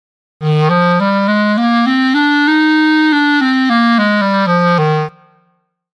groep6_les1-5-2_blaasinstrumenten4_klarinet.mp3